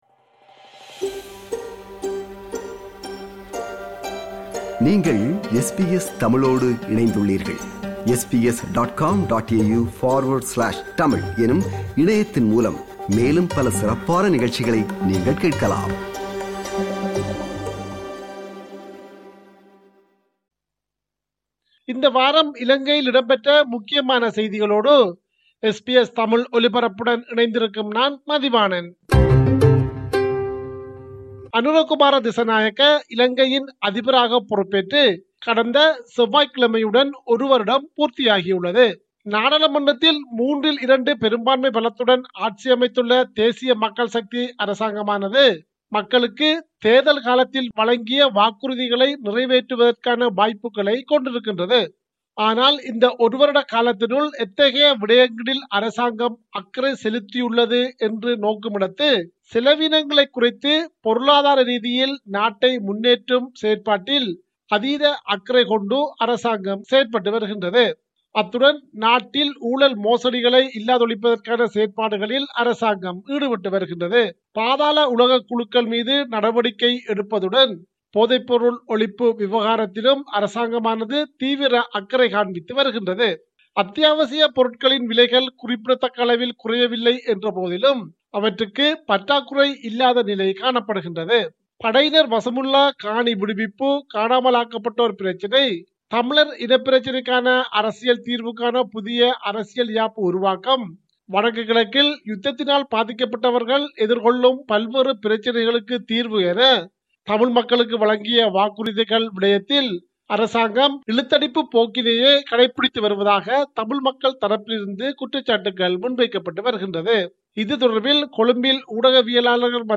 இலங்கை: இந்த வார முக்கிய செய்திகள்